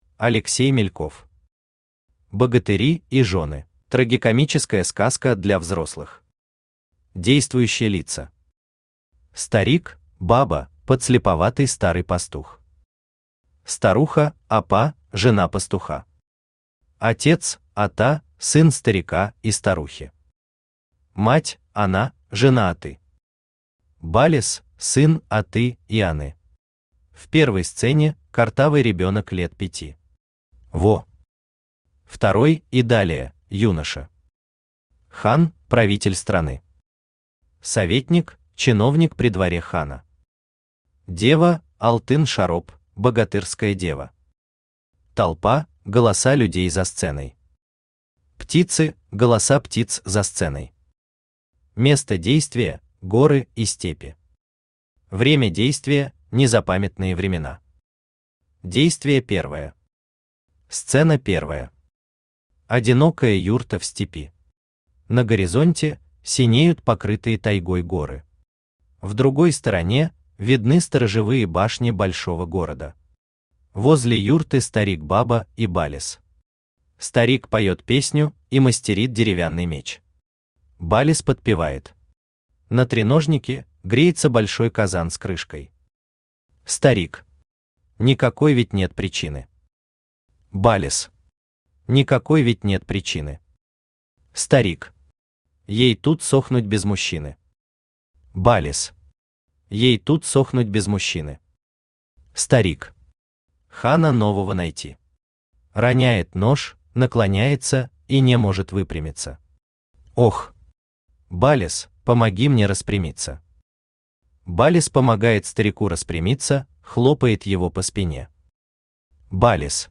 Читает: Авточтец ЛитРес
Аудиокнига «Богатыри и жёны».